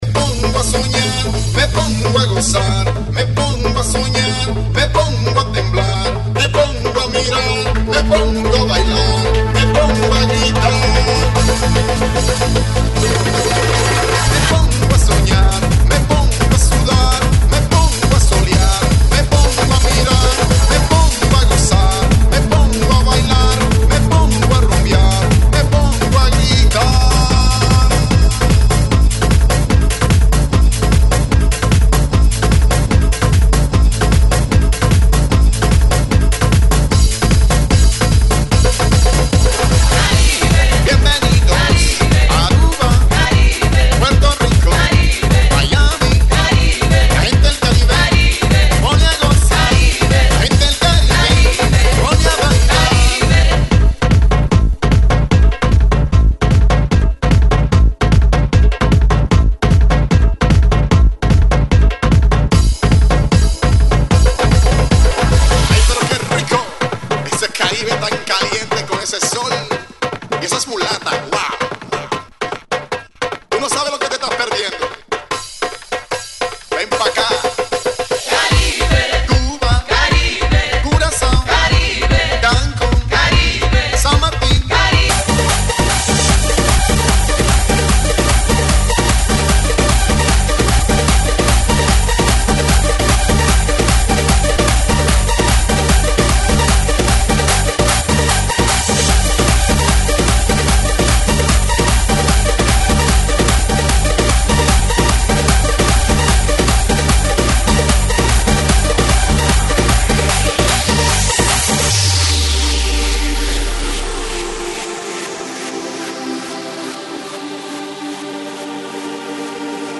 GENERO: ELECTRO – LATIN